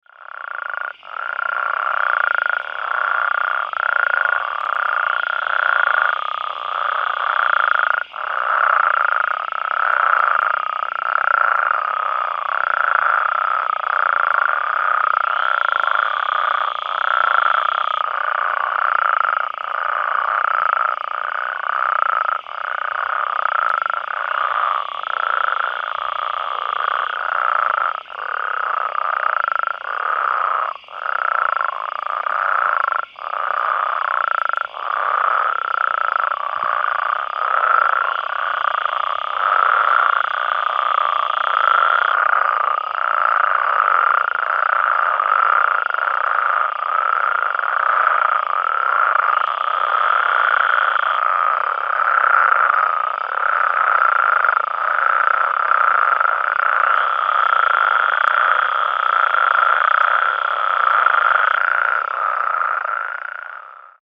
Chihuahuan Desert Spadefoot  - Spea multiplicata stagnalis
Advertisement Calls
It is produced by a male frog in order to attract females during the breeding season and to warn other rival males of his presence.
Sound  This is a 64 second recording of a group of spadefoots calling at night while floating in a pool in a flooded wash (shown on the left) in Hidalgo County, New Mexico. A Western Green Toad and possibly some Great Plains Spadefoots can also be heard in this chorus along with Great Plains Toads in the distance.